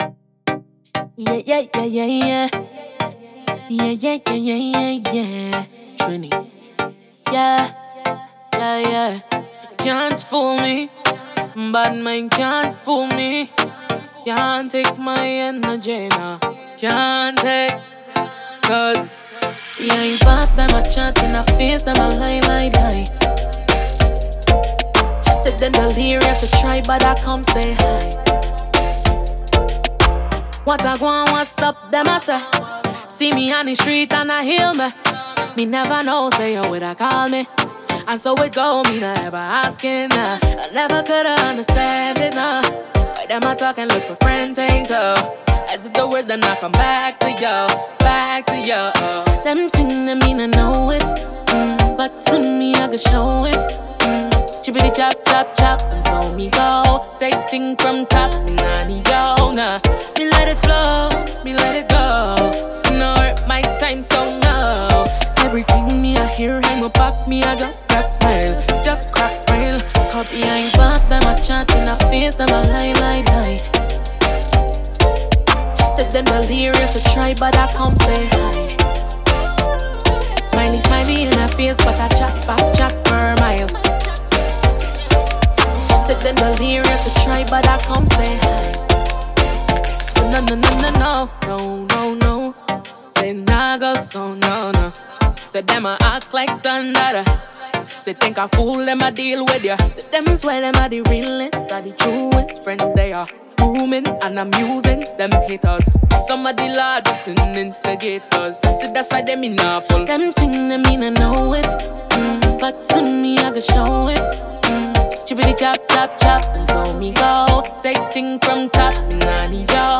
Genre: Telugu